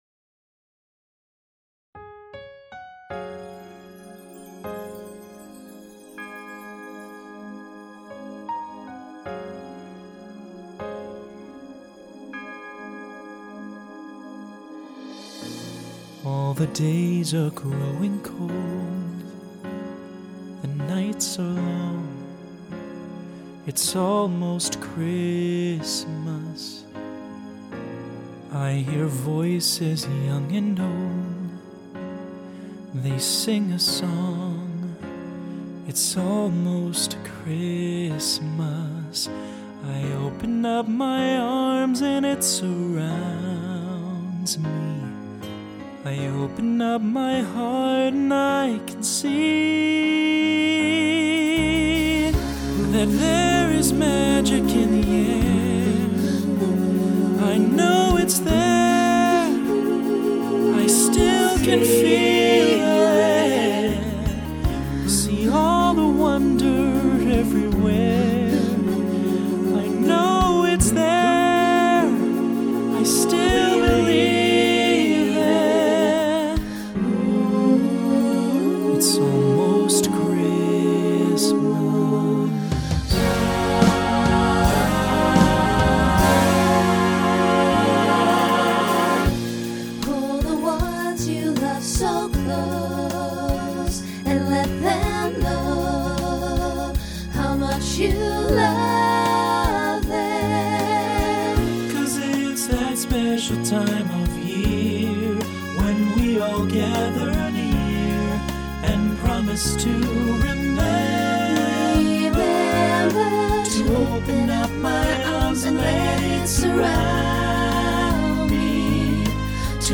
Ballad Voicing SATB